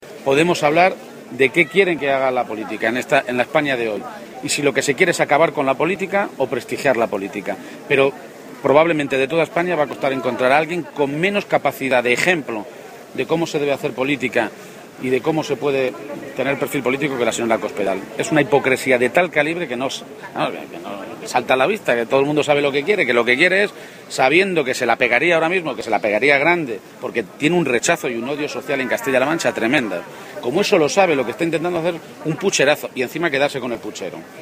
García-Page se ha pronunciado así en Puertollano, localidad a la que ha acudido para participar en sus fiestas patronales, un escenario en el que ha criticado el afán de Cospedal por querer acabar con la política o desprestigiarla, quizá, ha sugerido, porque “probablemente en toda España va a costar encontrar a alguien con menos capacidad de ejemplo de cómo se debe hacer política como la señora Cospedal”.